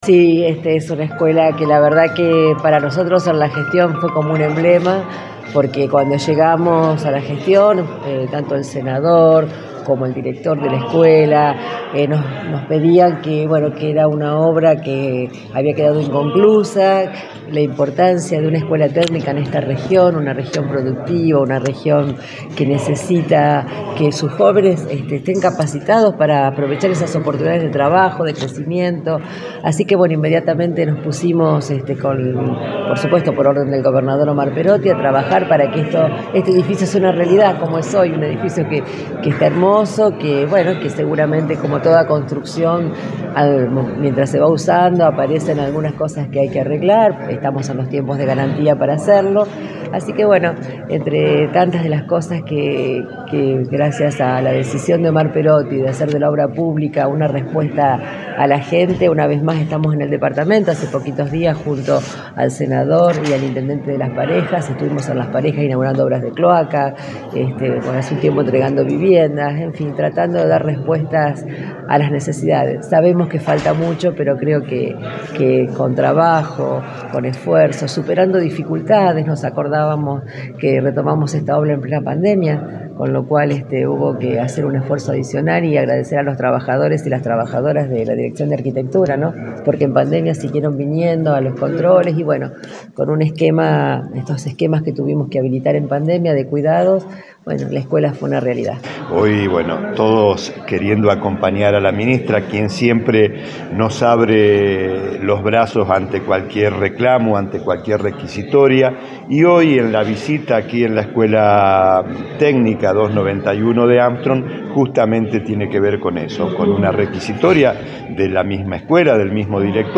Audio de Frana en Armstrong/ Audio Senador Cornaglia/ Concejal Dr. Augusto Fischer.